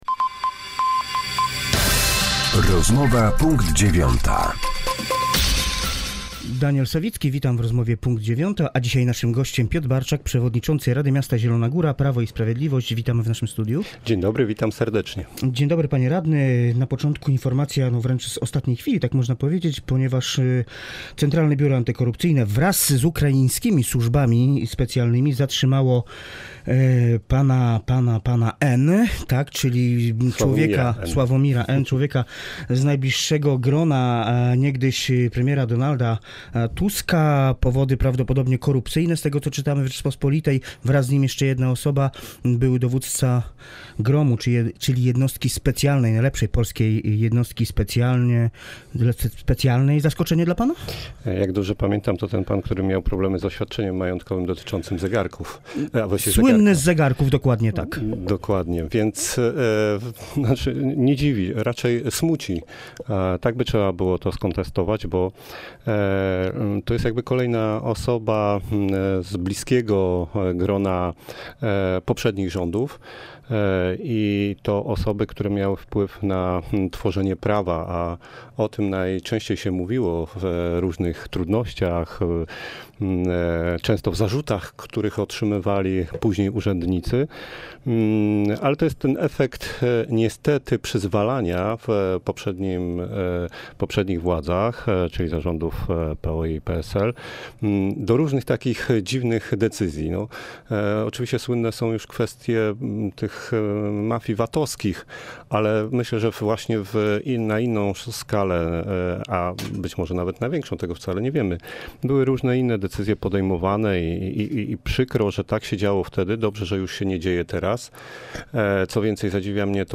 Z przewodniczącym rady miasta Zielona Góra, radnym klubu Prawo i Sprawiedliwość